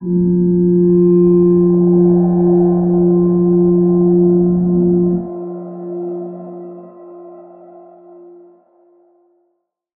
G_Crystal-F4-f.wav